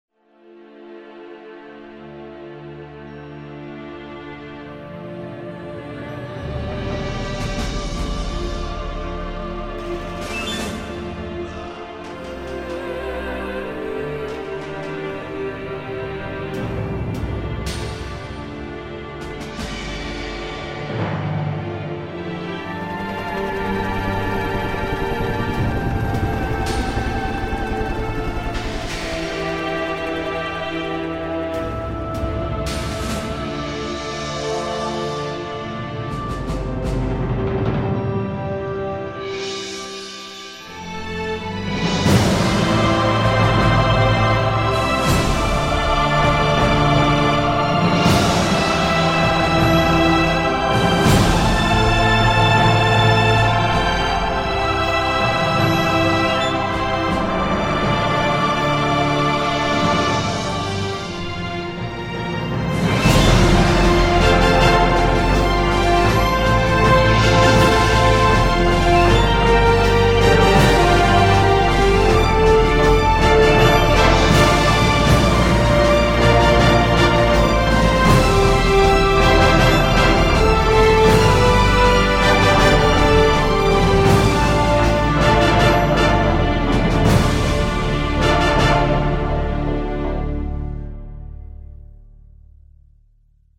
.: orchestral / movie style :.